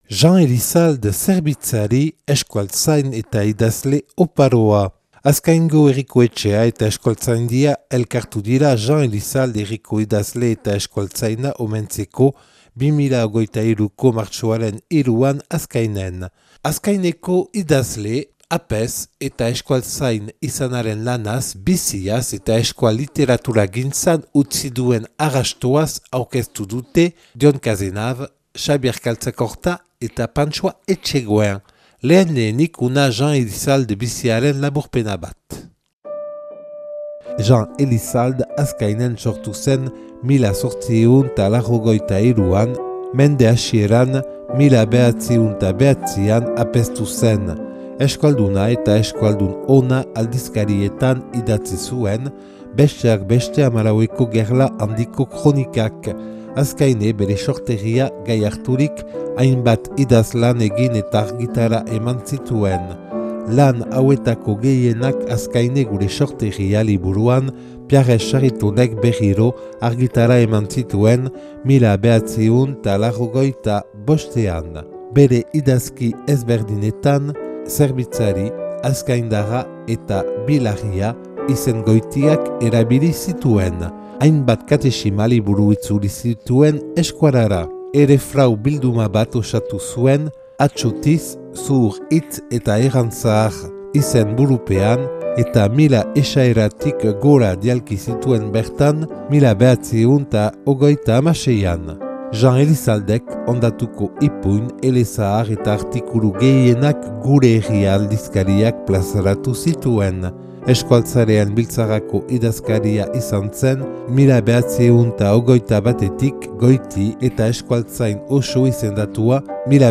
Azkaingo Herriko Etxea eta Euskaltzaindia elkartu dira Jean Elissalde herriko idazle eta euskaltzaina omentzeko 2023.